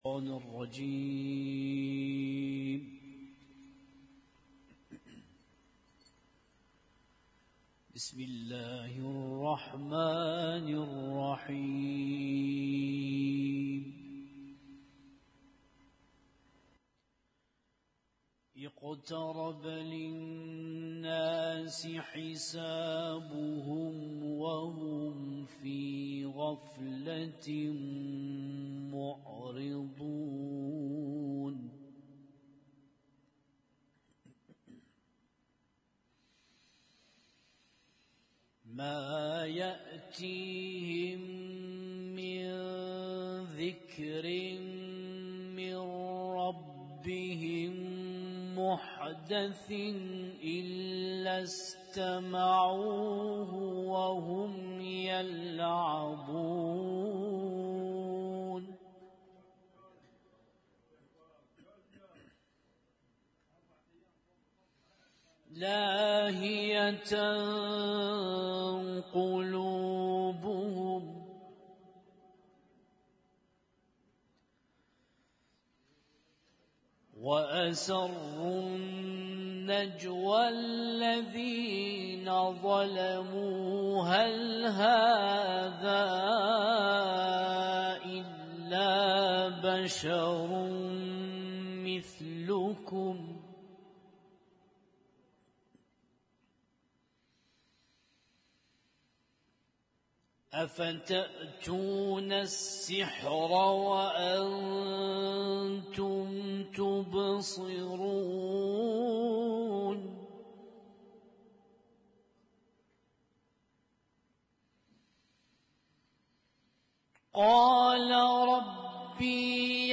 اسم التصنيف: المـكتبة الصــوتيه >> القرآن الكريم >> القرآن الكريم - القراءات المتنوعة